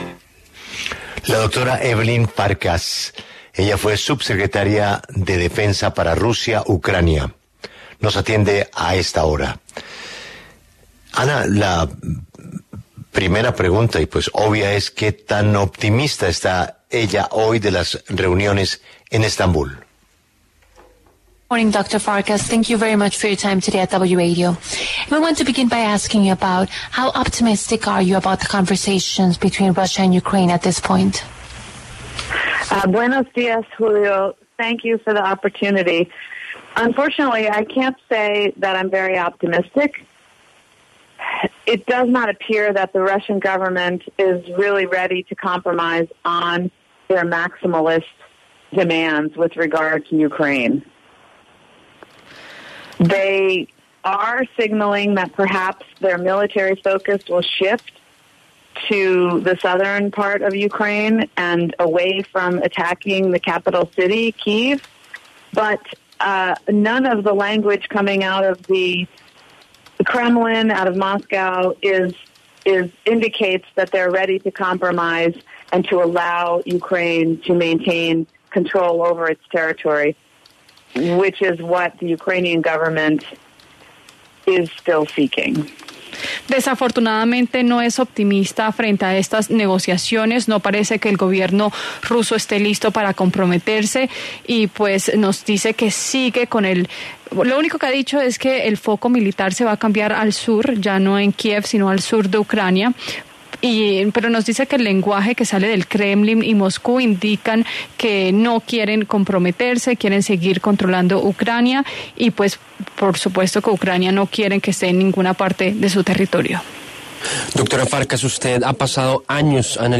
Evelyn Farkas, ex subsecretaria adjunta de Defensa para Rusia, Ucrania y Eurasia, habló en La W sobre el futuro de las negociaciones entre las delegaciones rusas y ucranianas en Turquía